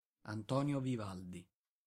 ^ English: UK: /vɪˈvældi/ viv-AL-dee, US: /vɪˈvɑːldi, -ˈvɔːl-/ viv-AHL-dee, viv-AWL-dee;[1][2][3] Italian: [anˈtɔːnjo ˈluːtʃo viˈvaldi]
It-Antonio_Vivaldi.ogg.mp3